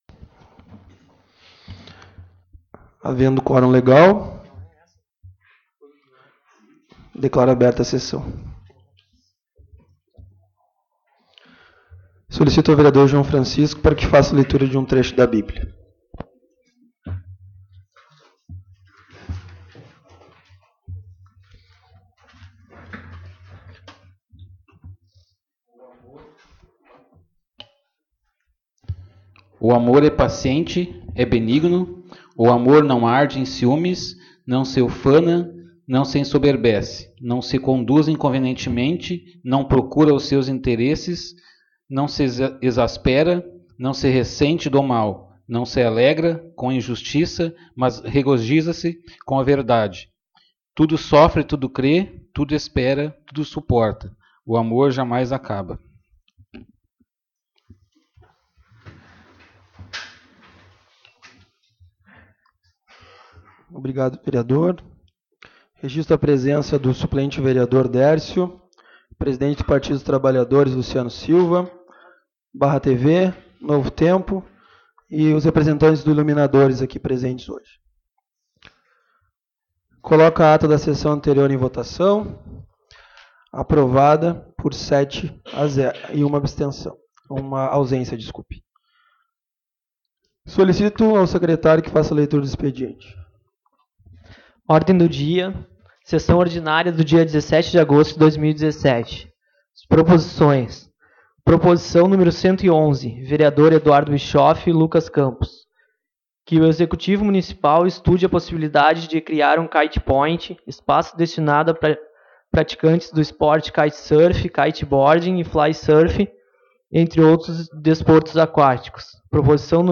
Publicação: 24/02/2021 às 11:06 Abertura: 24/02/2021 às 11:06 Ano base: 2017 Número: Palavras-chave: Anexos da publicação Áudio da Sessão Ordinária de 17.08.2017 às 19h. 24/02/2021 11:06 Compartilhar essa página...